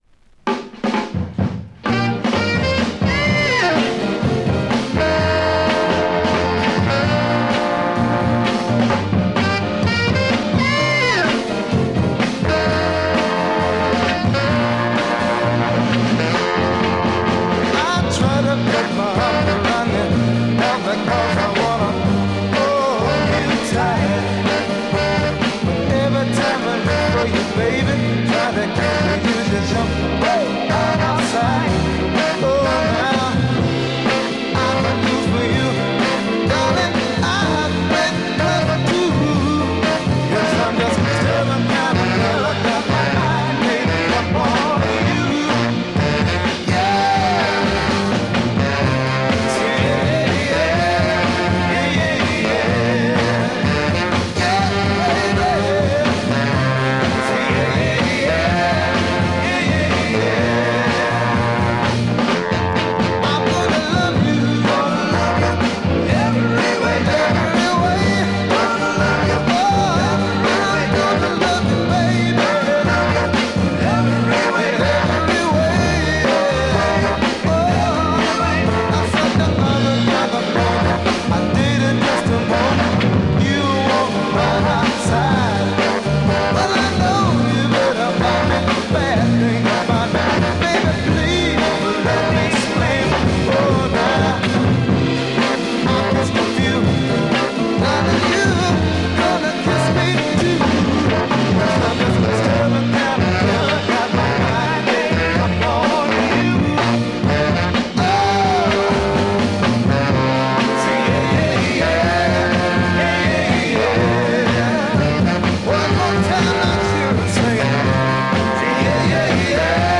Killer Soul Break Mod